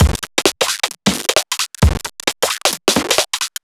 Index of /musicradar/uk-garage-samples/132bpm Lines n Loops/Beats
GA_BeatDCrush132-02.wav